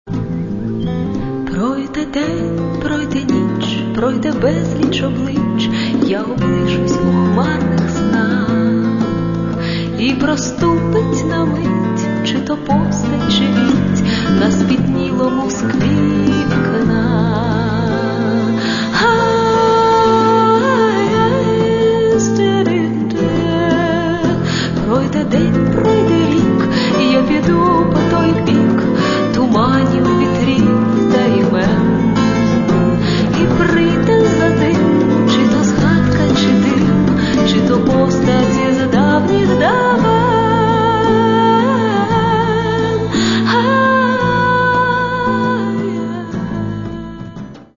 Catalogue -> Rock & Alternative -> Lyrical Underground
This group plays something between light jazz and art-rock.
guitars
violin
sopilka
drums, percussion